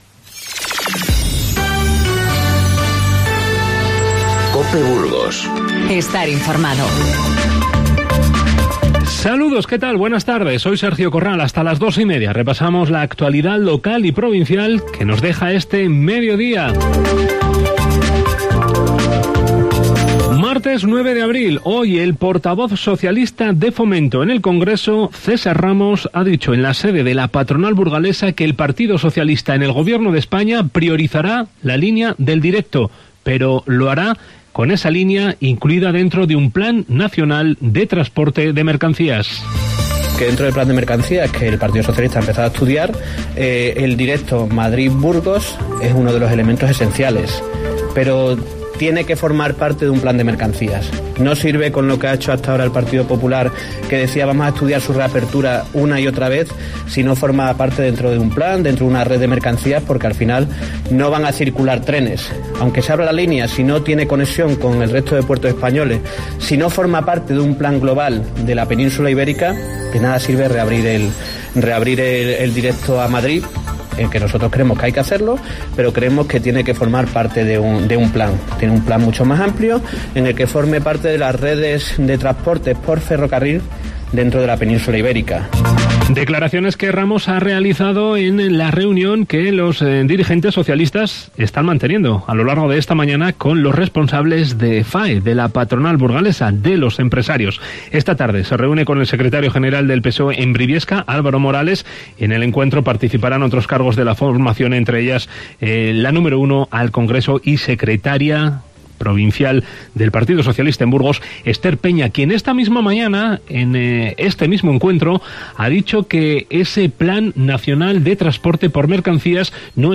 Informativo Mediodía COPE Burgos 09/04/19